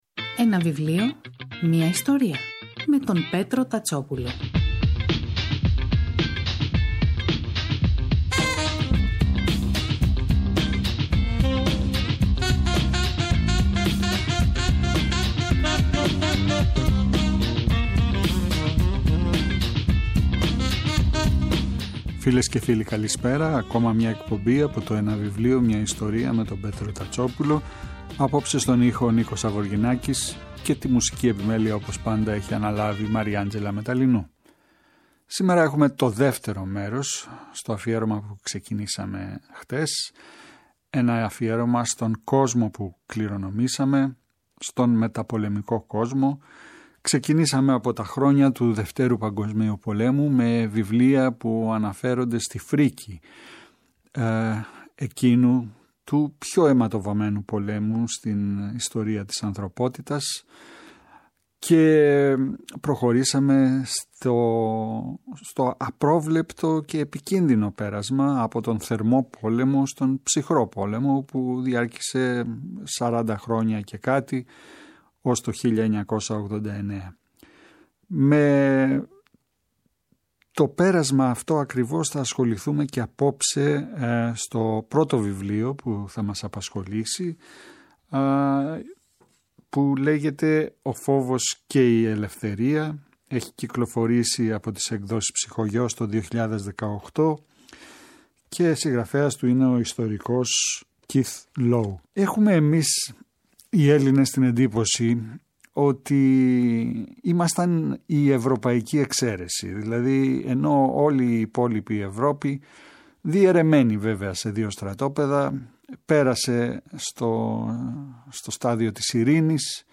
Αυτό το Σαββατοκύριακο, 11 και 12 Νοεμβρίου, στις 5 το απόγευμα, στη ραδιοφωνική εκπομπή «Ένα βιβλίο, μια ιστορία» στο Πρώτο Πρόγραμμα Διπλό αφιέρωμα στον «Κόσμο που κληρονομήσαμε» παρουσιάζονται και σχολιάζονται τα βιβλία :